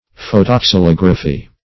Meaning of photoxylography. photoxylography synonyms, pronunciation, spelling and more from Free Dictionary.
Search Result for " photoxylography" : The Collaborative International Dictionary of English v.0.48: Photoxylography \Pho`to*xy*log"ra*phy\, n. [Photo- + xylography.] The process of producing a representation of an object on wood, by photography, for the use of the wood engraver.